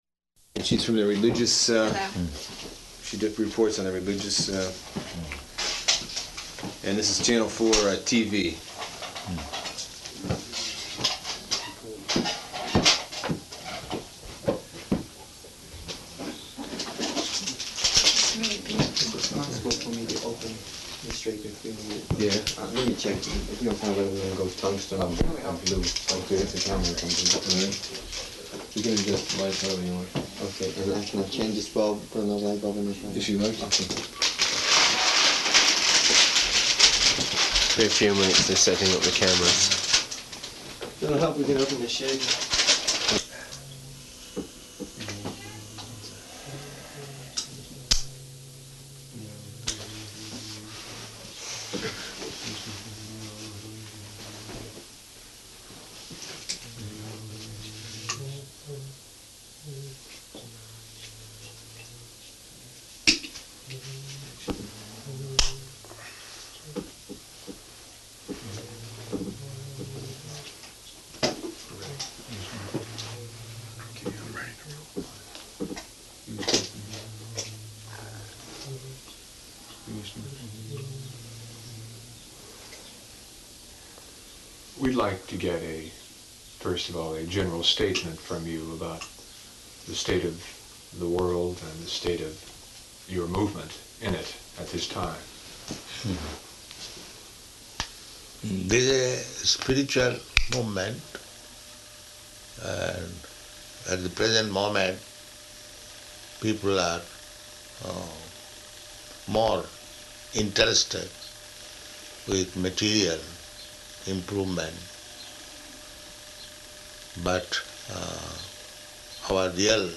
Interview and Conversation
-- Type: Interview Dated
[background talk setting up cameras, etc.]